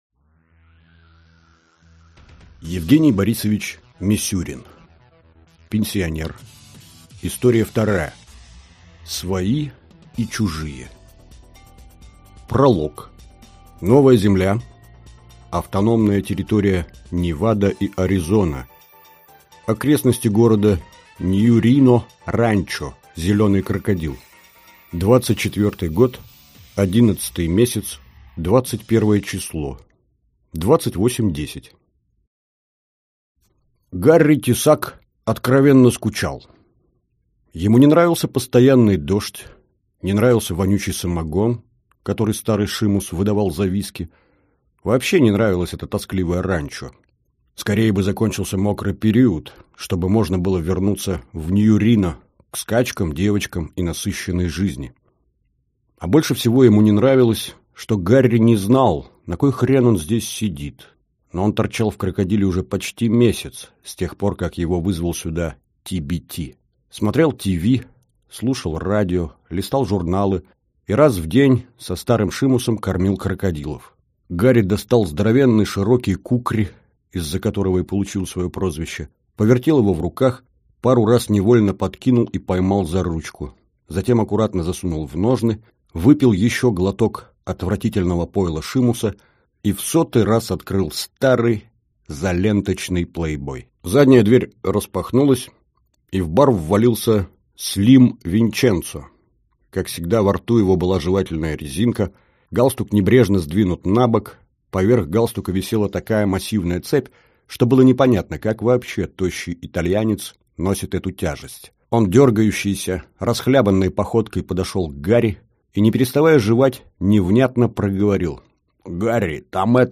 Аудиокнига Пенсионер. История вторая. Свои и чужие | Библиотека аудиокниг